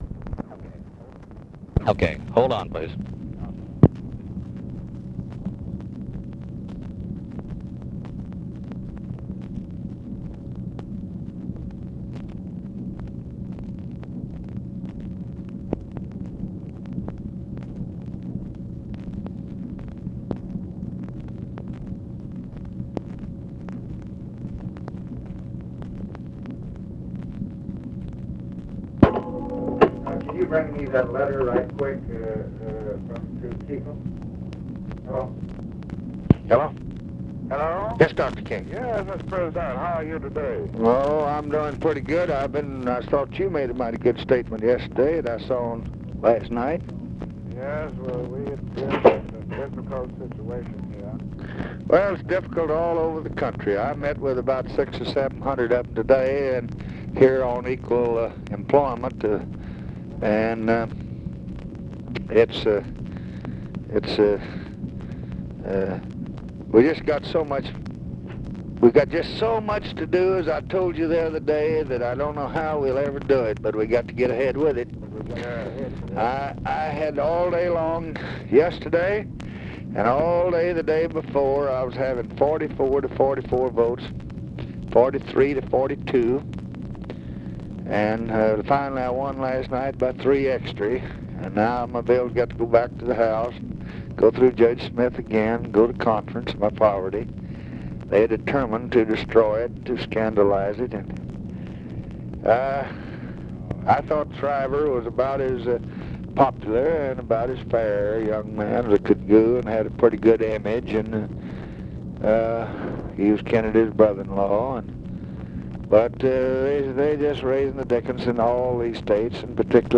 Conversation with MARTIN LUTHER KING, LEE WHITE and OFFICE CONVERSATION, August 20, 1965
Secret White House Tapes